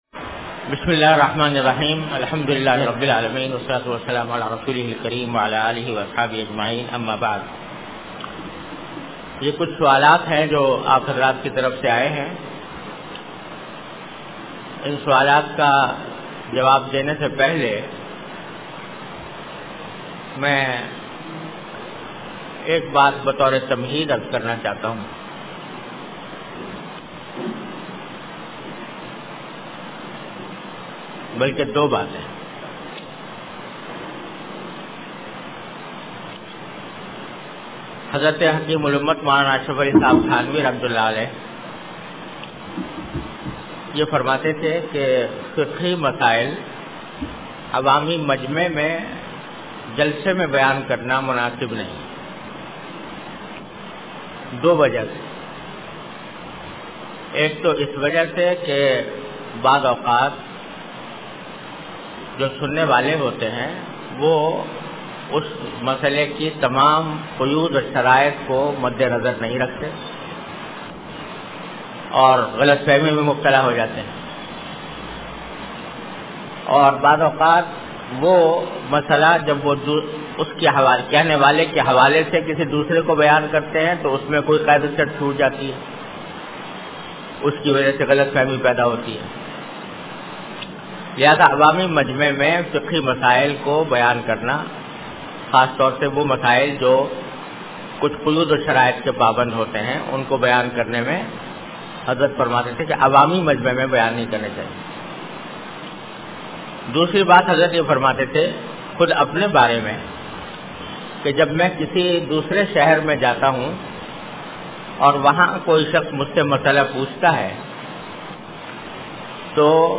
An Islamic audio bayan by Hazrat Mufti Muhammad Taqi Usmani Sahab (Db) on Bayanat.
Event / Time After Isha Prayer